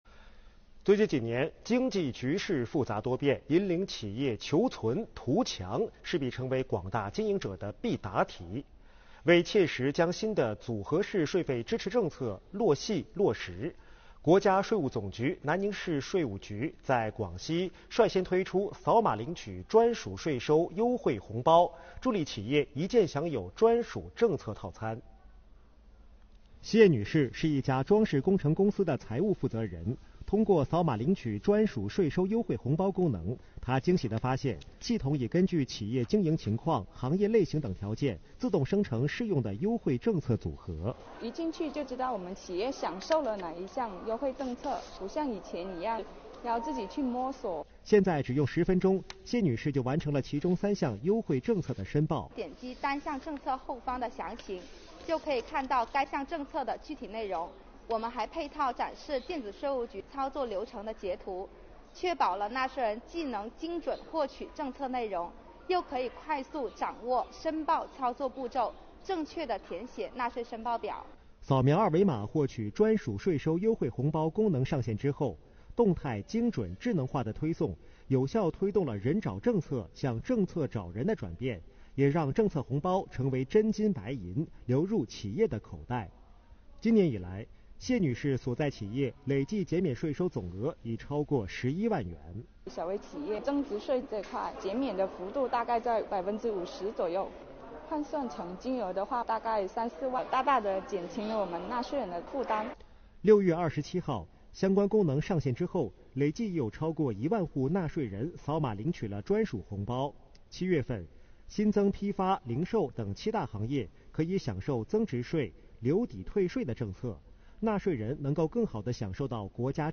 电视报道：“红包雨”来袭 纳税人“专属税收优惠”可扫码领取